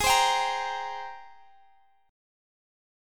Listen to G#M9 strummed